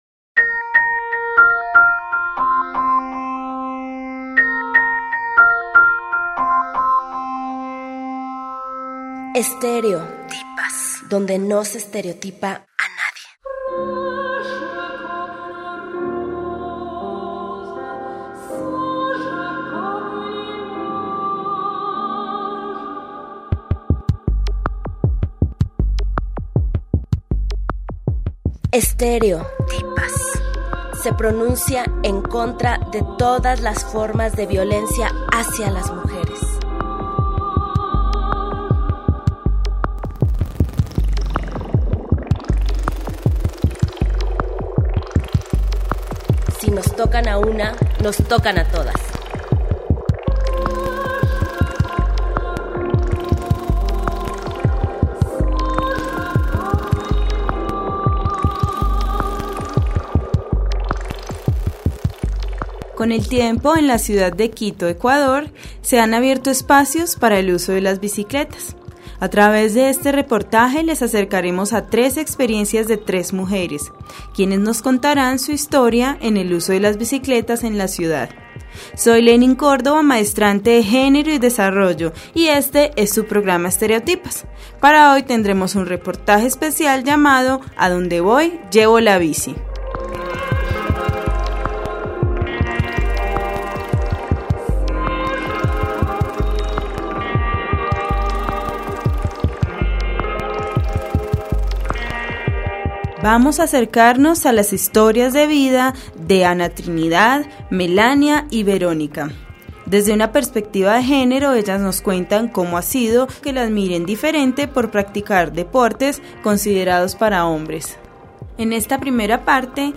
A través de este reportaje les acercaremos a tres experiencias de tres mujeres, quienes nos contarán su historia en el uso de las bicicletas en la ciudad.